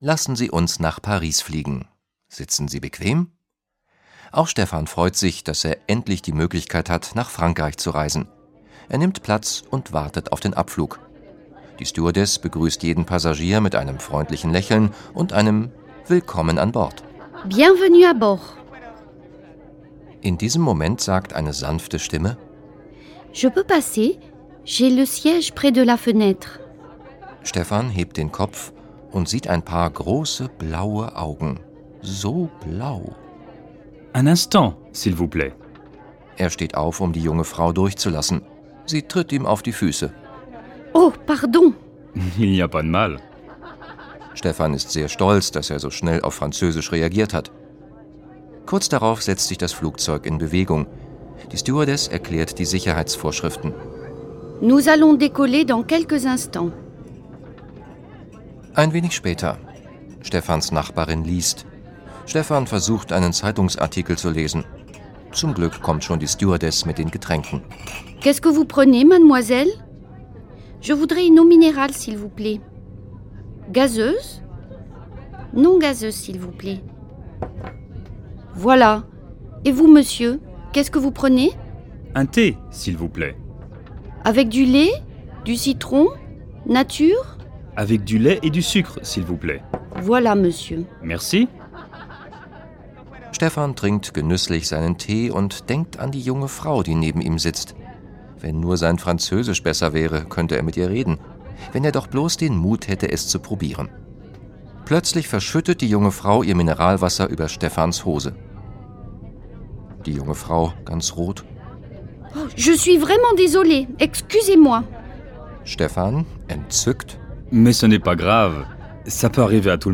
Der Sprachkurs zum Hören mit 4 Audio-CDs und Begleitheft
Dialoge mit Übersetzung